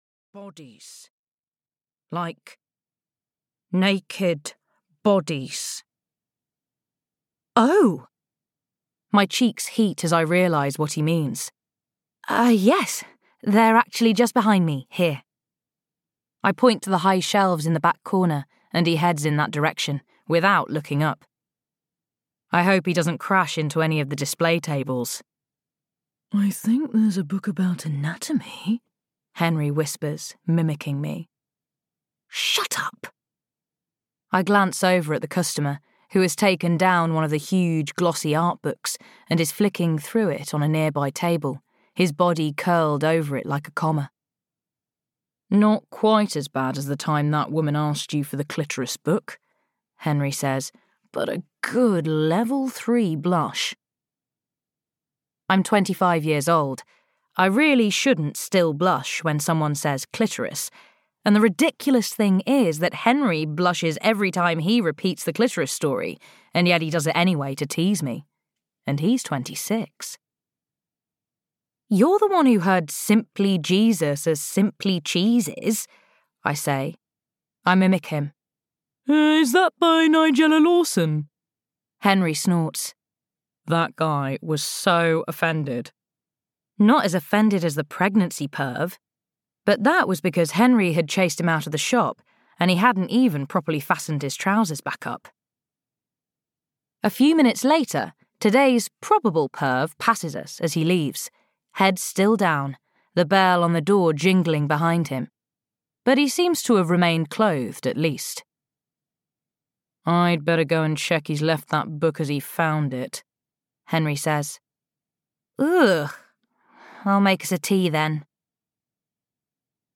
It Had to Be You (EN) audiokniha
Ukázka z knihy